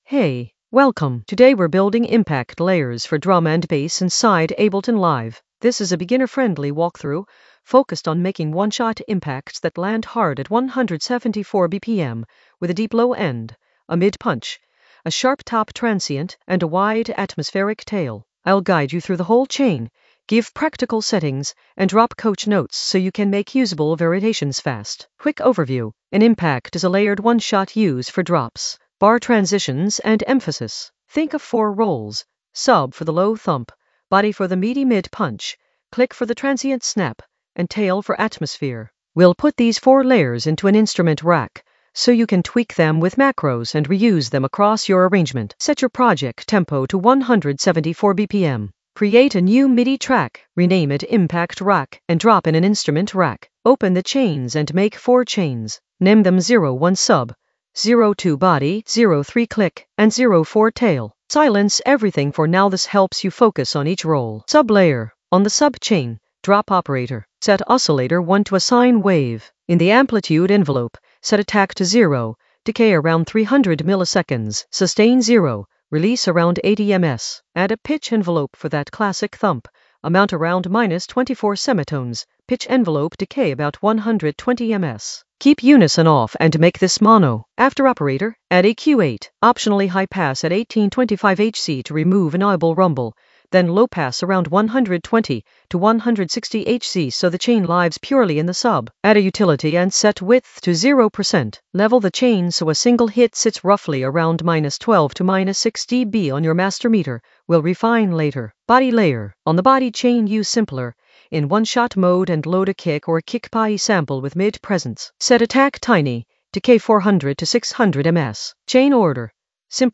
An AI-generated beginner Ableton lesson focused on Impact layering basics in the FX area of drum and bass production.
Narrated lesson audio
The voice track includes the tutorial plus extra teacher commentary.
Teacher tone: energetic, clear, professional ⚡